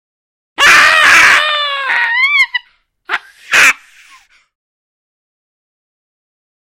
На этой странице собраны разнообразные звуки бабуина — от громких криков до ворчания и общения в стае.
Звук пронзительного крика бабуина